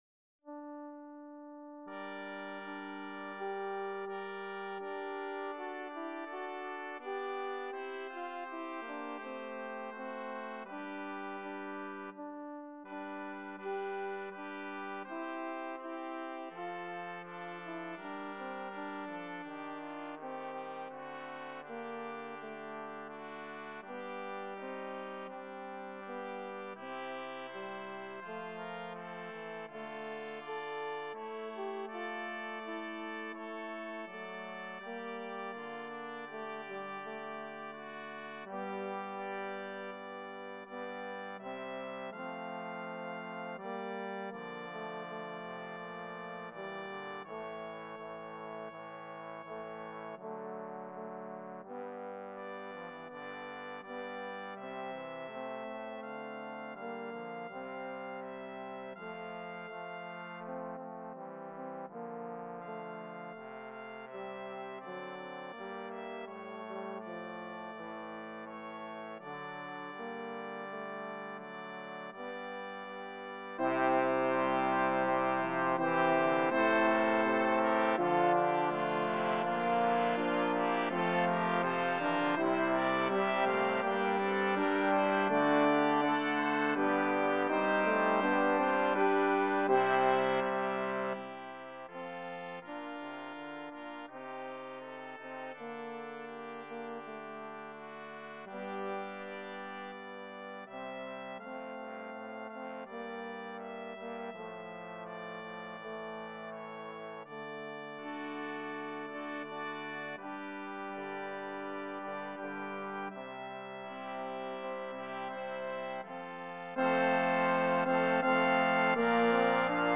DOUBLE BRASS CHOIR